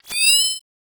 Holographic UI Sounds 76.wav